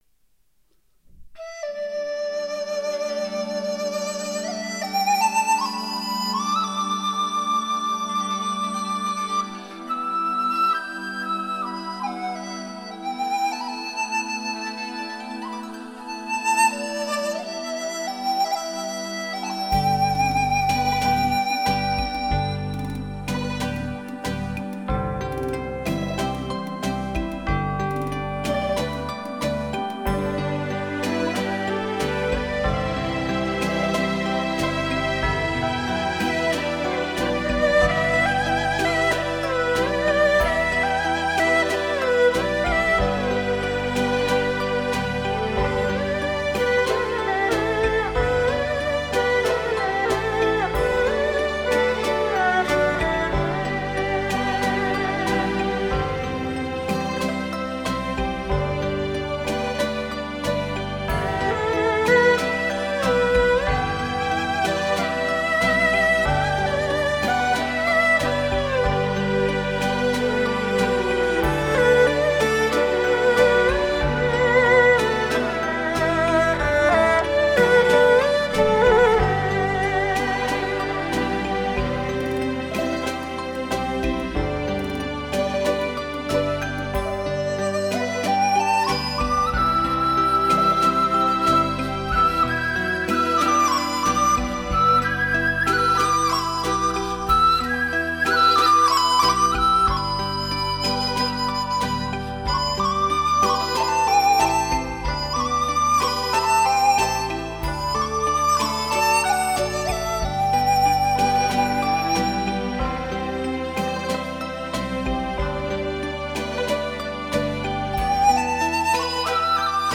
DTS格式的音乐更会体现其方位感和真实感。